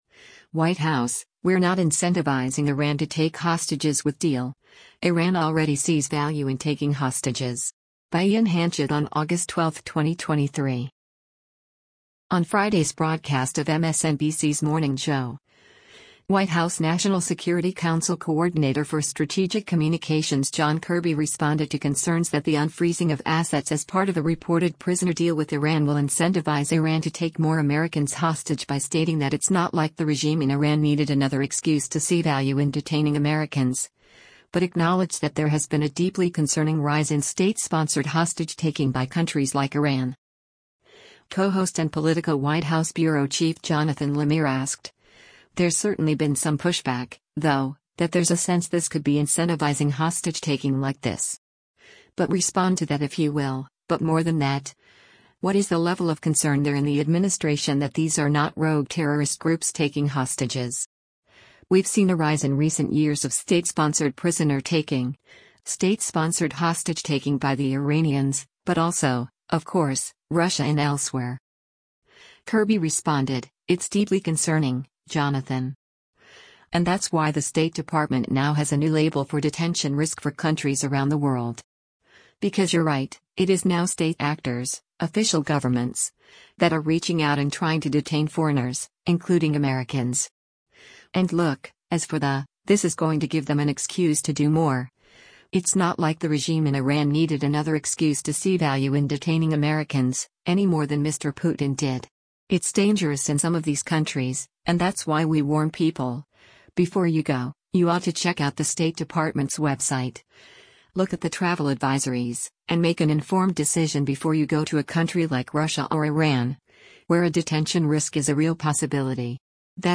On Friday’s broadcast of MSNBC’s “Morning Joe,” White House National Security Council Coordinator for Strategic Communications John Kirby responded to concerns that the unfreezing of assets as part of a reported prisoner deal with Iran will incentivize Iran to take more Americans hostage by stating that “it’s not like the regime in Iran needed another excuse to see value in detaining Americans,” but acknowledged that there has been a “deeply concerning” rise in state-sponsored hostage-taking by countries like Iran.